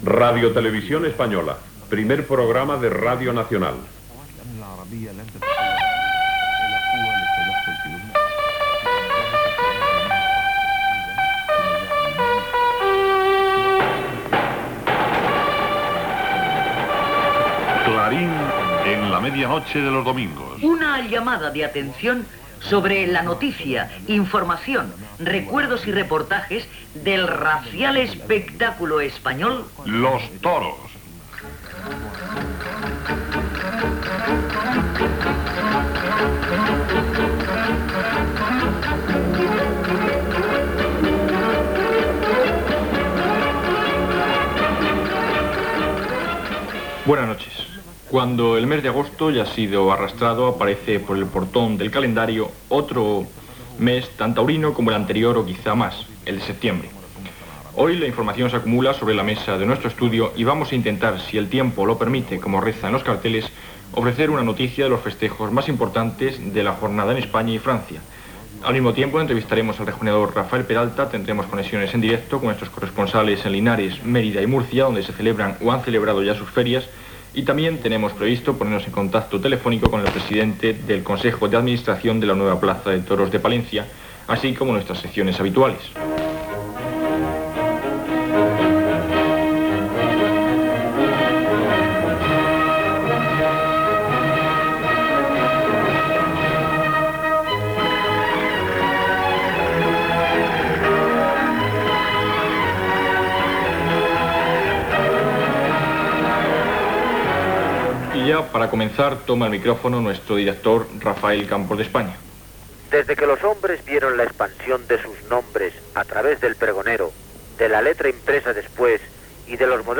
Identificació de l'emissora, com RTVE Primer Programa de Radio Nacional, careta del programa, sumari de continguts, comentari d'actualitat taurina
Informatiu